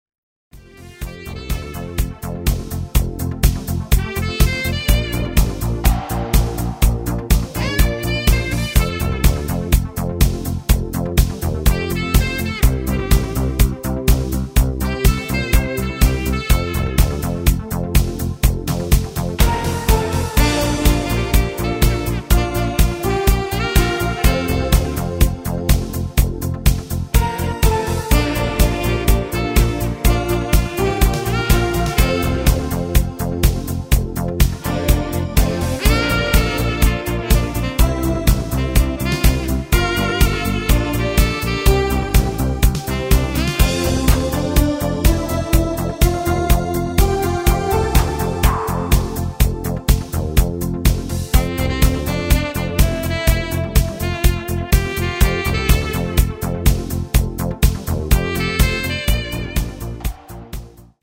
Instrumental Sax